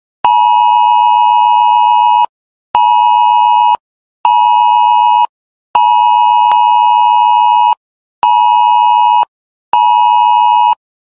מידע שימושי - "ירי רקטות וטילים" - רינגטונים AI להנחיות פיקוד העורף • שאגת הארי
זהירות, צליל של התראה מקדימה (מלחיץ מאוד) יש לך גם את התרעת הסיום?